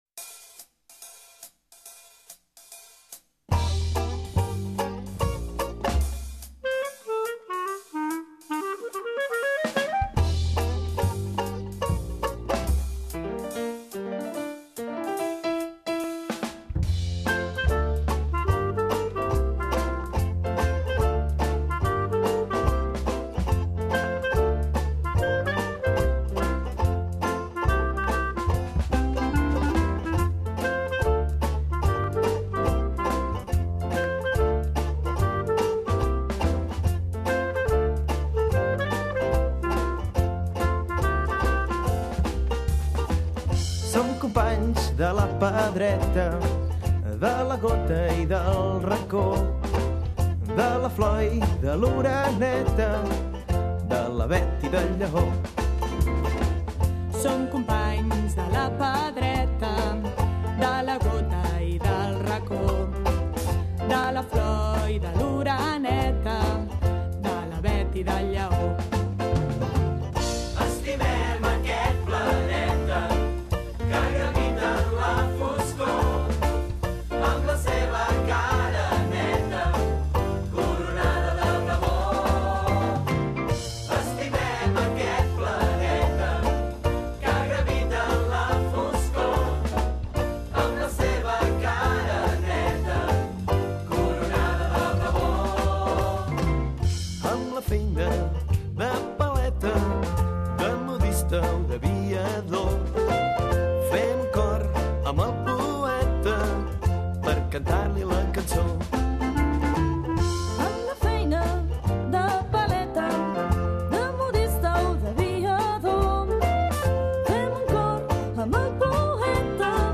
Lletra: Miquel Desclot / Música: Roger Canals
Swing